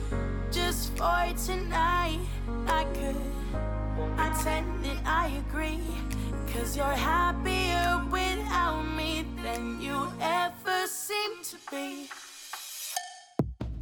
【ステレオソングからボーカルを分離】